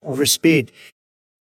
overspeed.wav